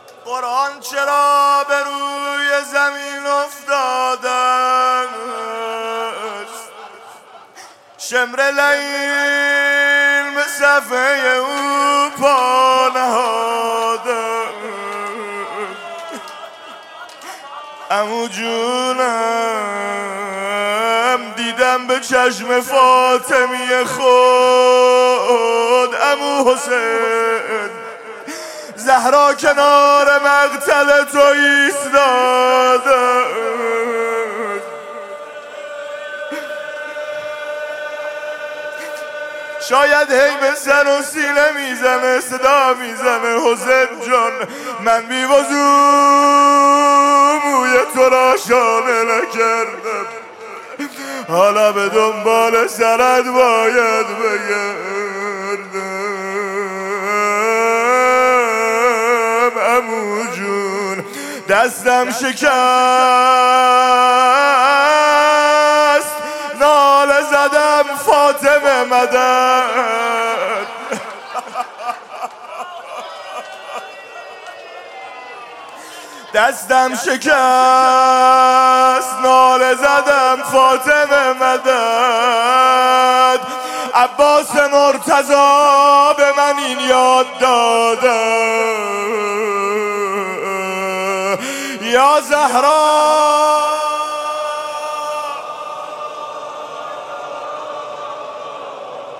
music-icon روضه